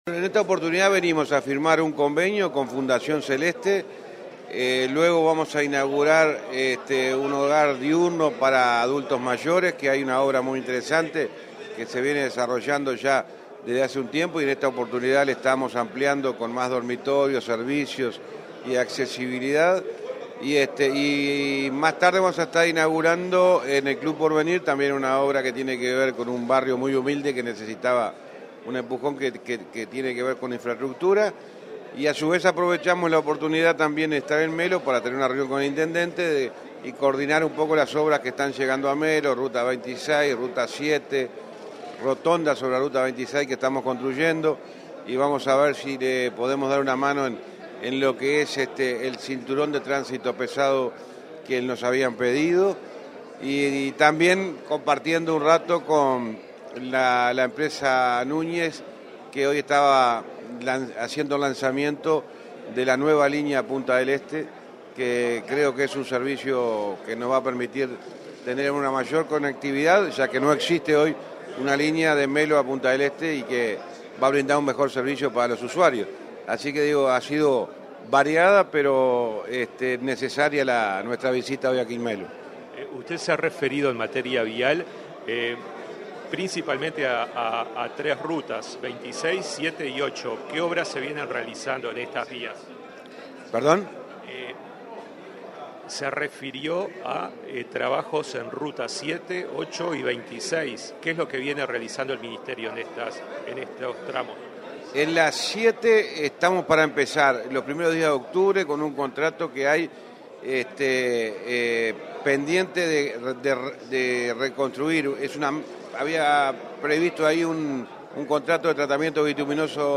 Entrevista al ministro de Transporte y Obras Públicas, José Luis Falero
Durante su visita a Cerro Largo, este 19 de setiembre, el ministro de Transporte, José Luis Falero, inauguró el hogar de la Asociación para la Integración del Adulto Mayor y firmó un convenio para obras en la ruta 7, entre otras actividades. Previo a los eventos, el jerarca realizó declaraciones a Comunicación Presidencial.